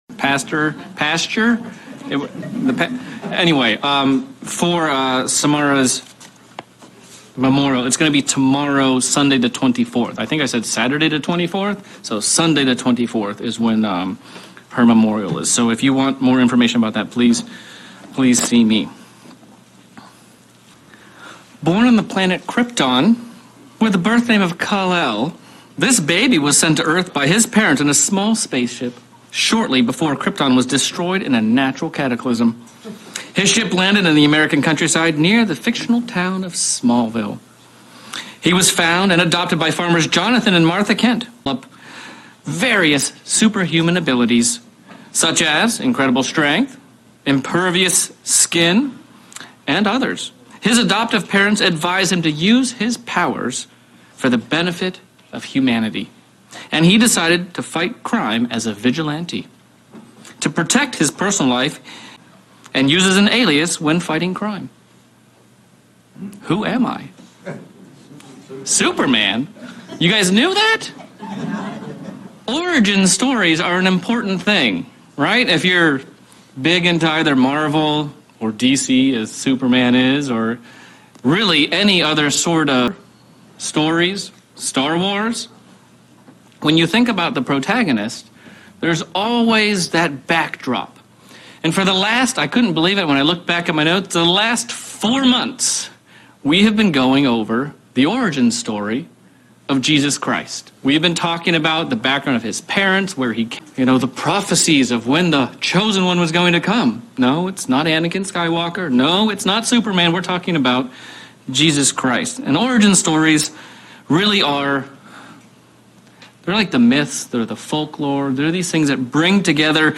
Sermon reflecting the promises of God throughout scripture and the evidence that those promises will be fulfilled. We can use this scriptural evidence to be assured of the things we have seen